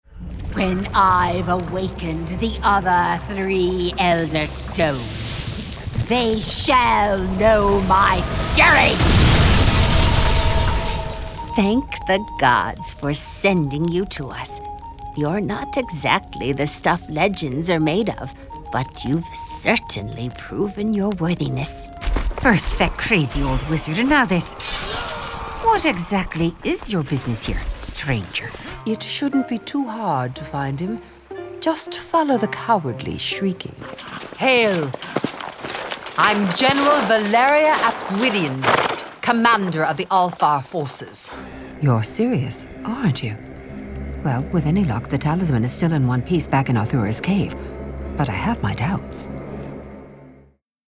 Looking for voice-over services?
video-Games-Demo.mp3